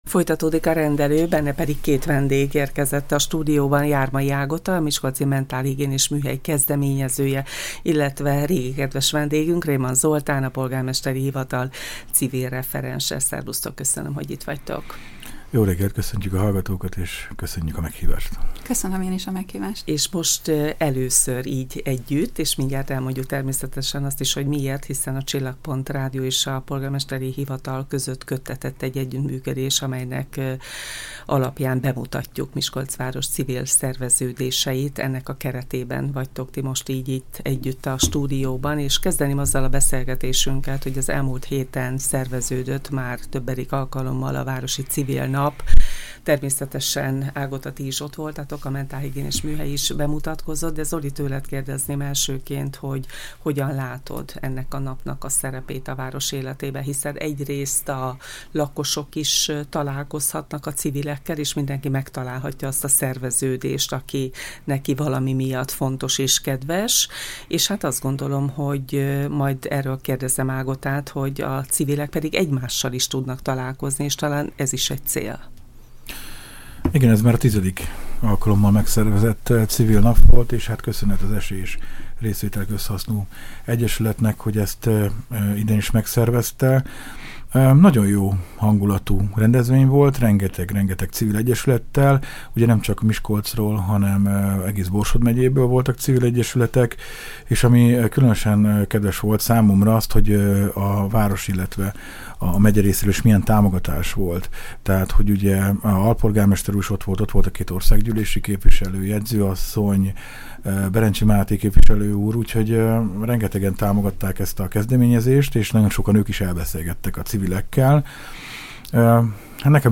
A stúdióbeszélgetés